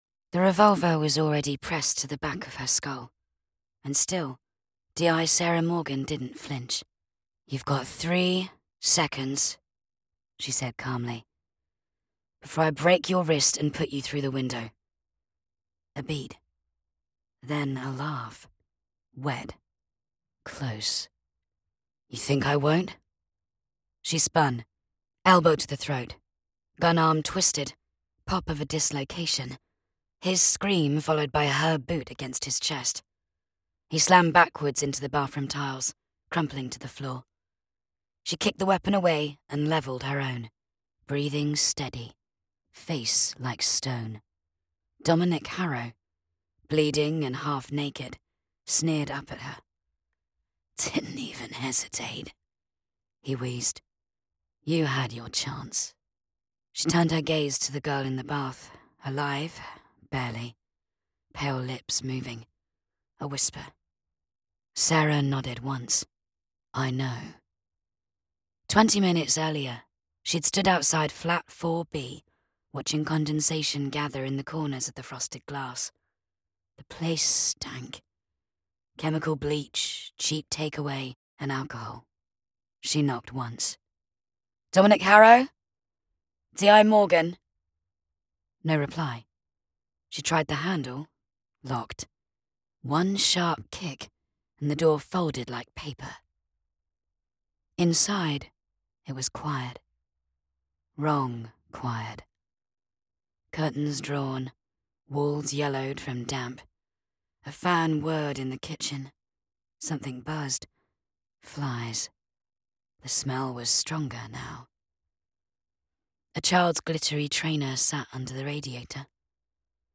British Female Audiobook Narrator
Crime Thriller Download
3rd person, F/M, English. Tense, dark.